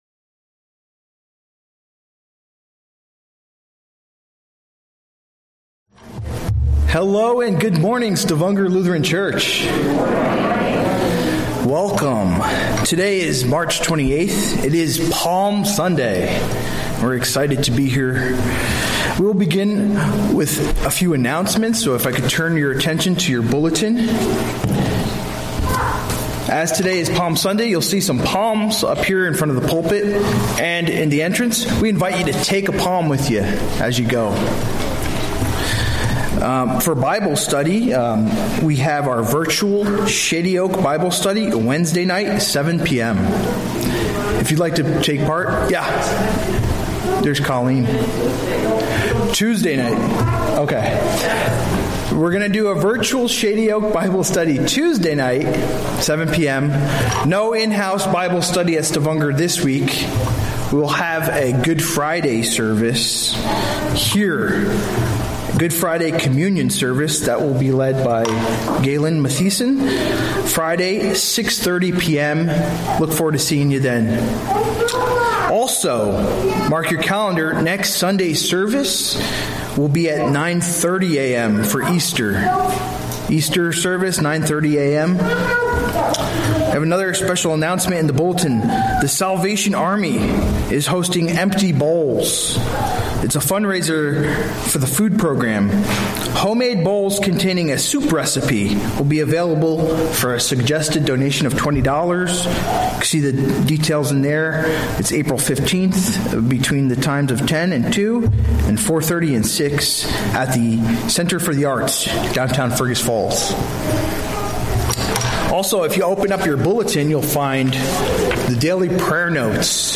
A message from the series "Sunday Worship." Cornerstone - Matthew 21:33-46